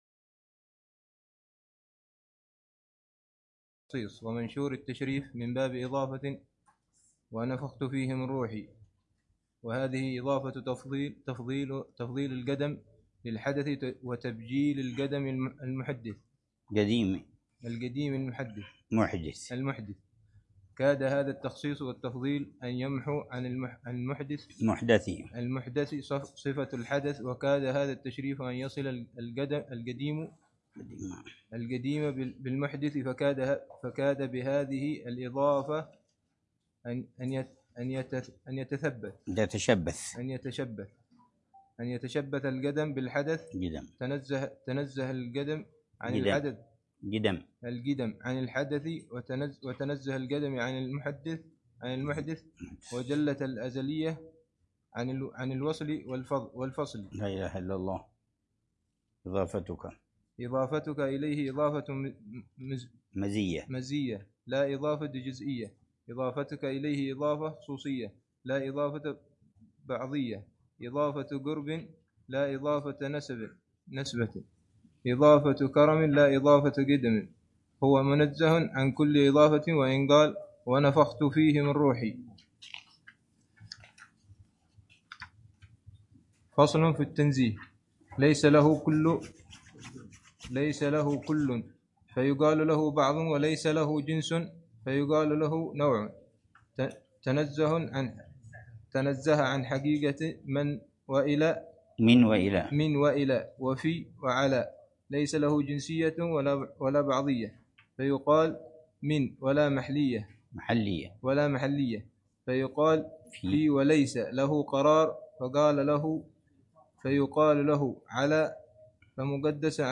الدرس الثاني عشر للعلامة الحبيب عمر بن محمد بن حفيظ في شرح كتاب: الكبريت الأحمر و الأكسير الأكبر في معرفة أسرار السلوك إلى ملك الملوك ، للإمام